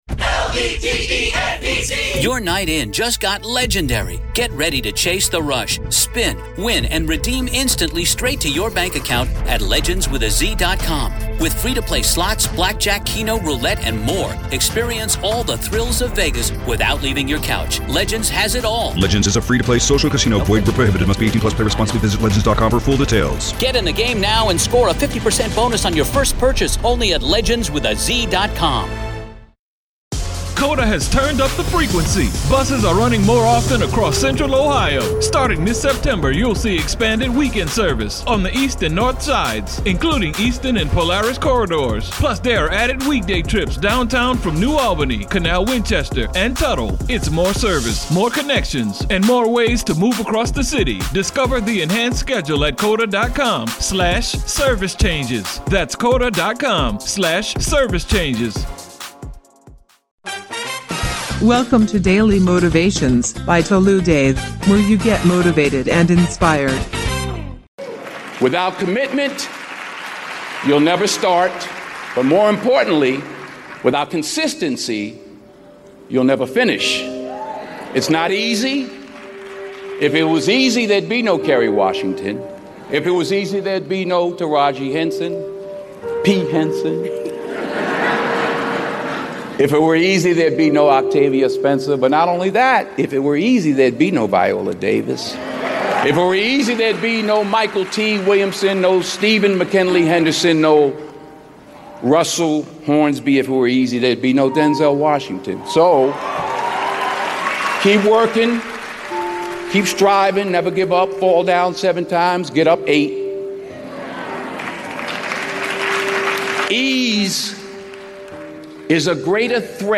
Speaker: Denzel Washington